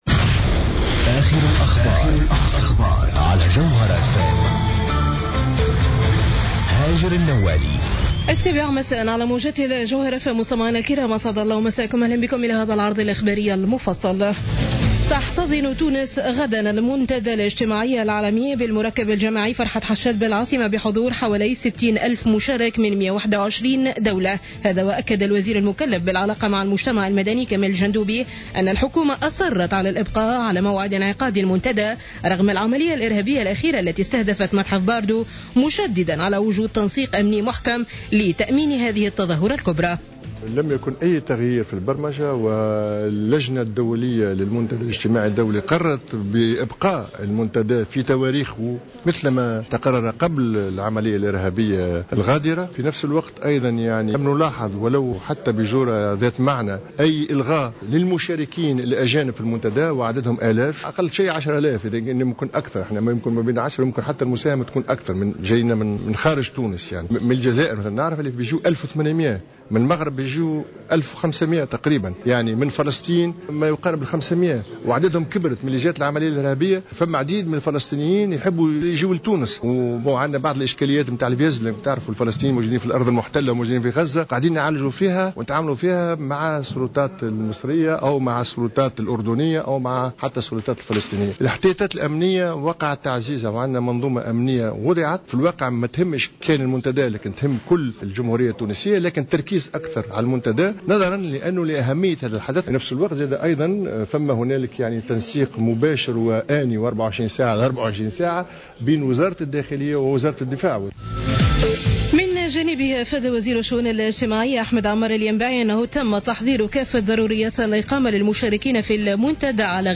نشرة أخبار السابعة مساء ليوم الاثنين 23 مارس 2015